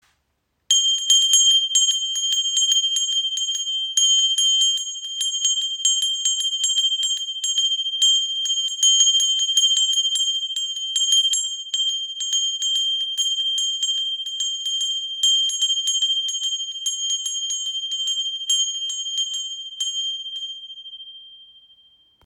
• Icon Klarer Klang – Reinigt die Atmosphäre und schafft Präsenz
Kleine Tempelglocke – Klang der Zeitlosigkeit
Diese kleine indische Tempelglocke besticht durch ihren klaren Klang und eine einzigartige Patina, die ihr eine zeitlose Ausstrahlung verleiht.
Ein sanfter Schlag – und ihr reiner Klang durchbricht die Stille.
• Material: Messing mit natürlicher Patina
• Höhe: 9 cm, ø 7 cm